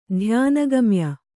♪ dhyāna gamya